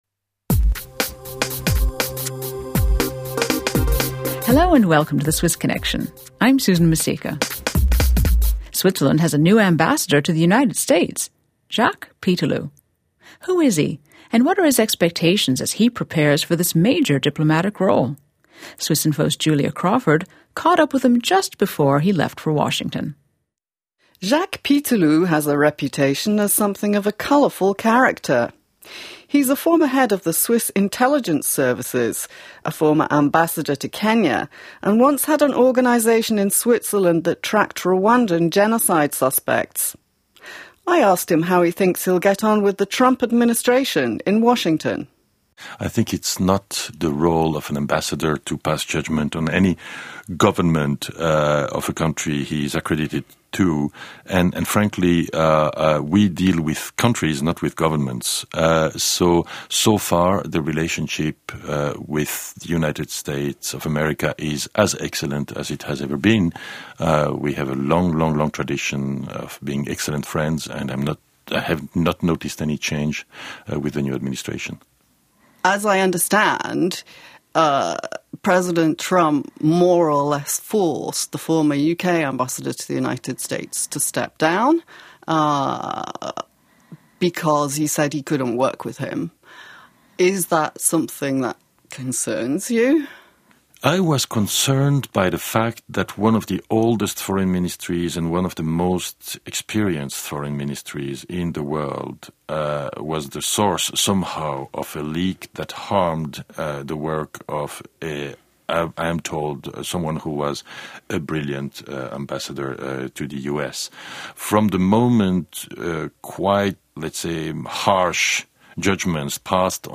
Interview with Jacques Pitteloud